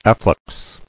[af·flux]